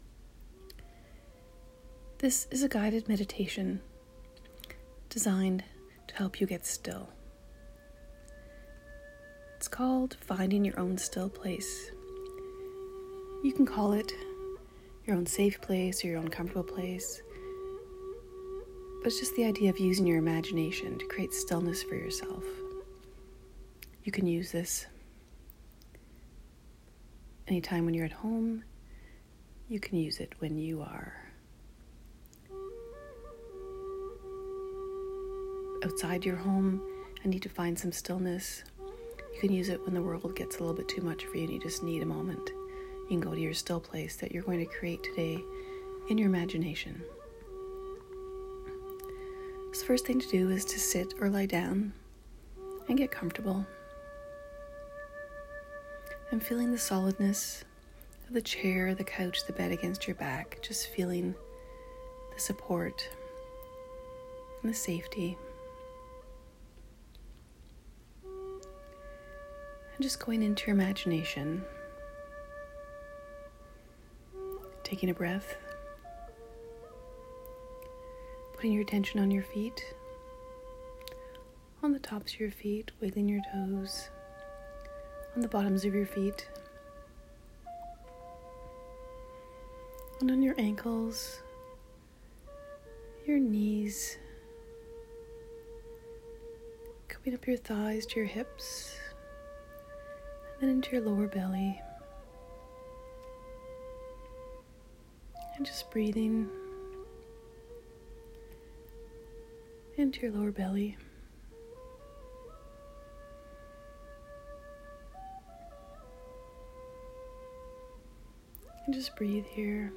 Find Your Own Still Place This guided meditation helps you create your own still place. You use your imagination to create a space that feels still, safe, and comfortable to you.